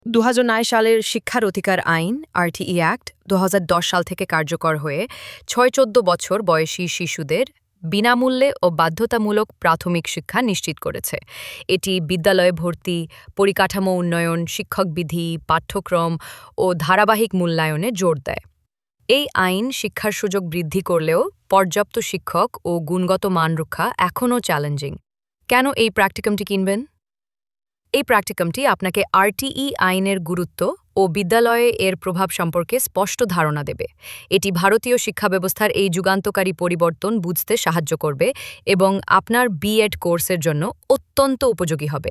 A short audio explanation of this file is provided in the video below.